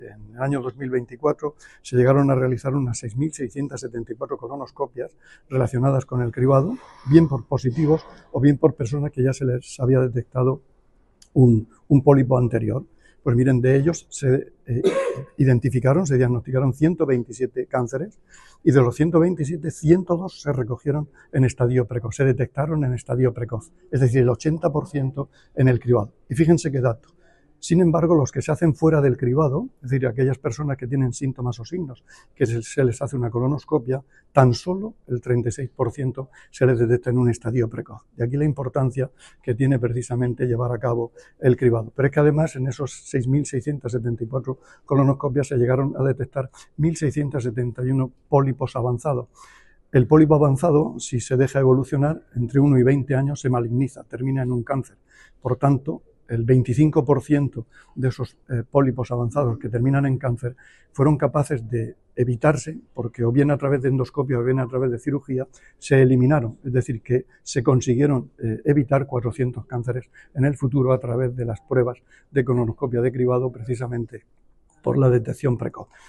Sonido/ Declaraciones del consejero de Salud, Juan José Pedreño, sobre los datos del cribado de cáncer de colorrectal.
El consejero de Salud, Juan José Pedreño, visitó hoy el centro de salud de El Ranero con motivo del Día Mundial contra el Cáncer de Colon.